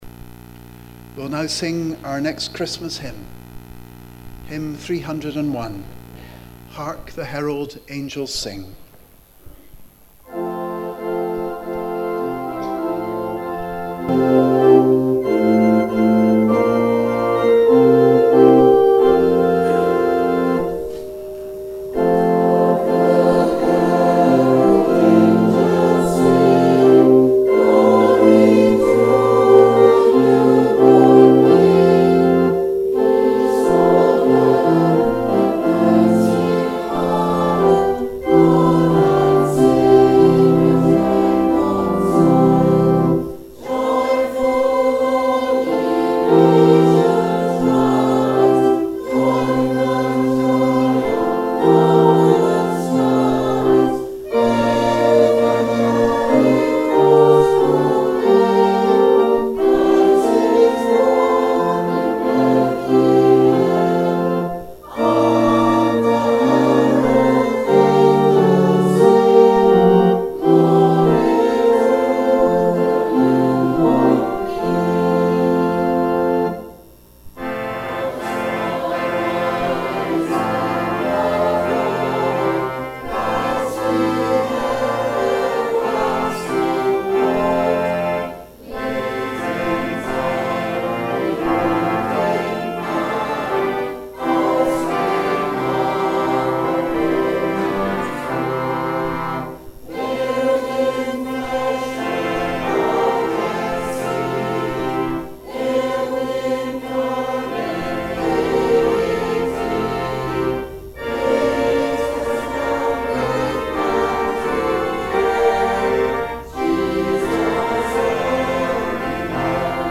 Christmas Eve at St. Mungo's - 24 December 2018
Watchnight Service and the celebration of Jesus birth
hymn 301 ‘Hark!